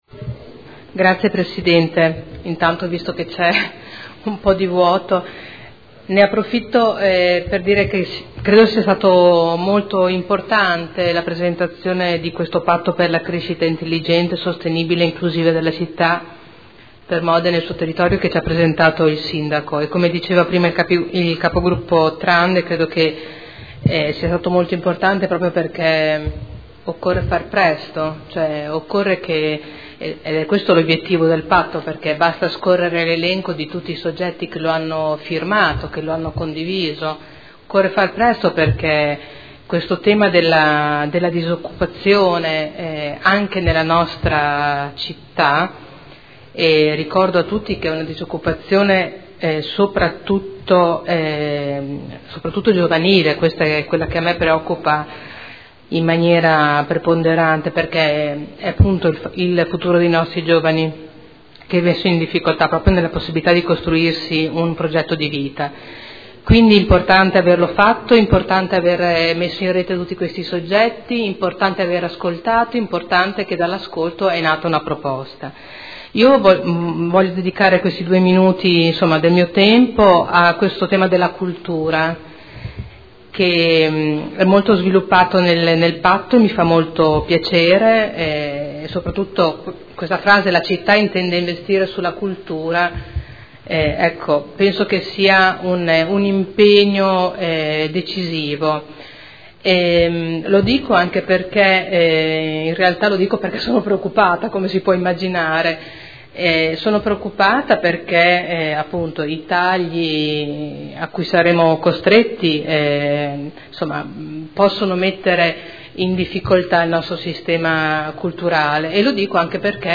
Seduta del 20/11/2014. Dibattito su Ordini del Giorno aventi per oggetto: "Patto per Modena"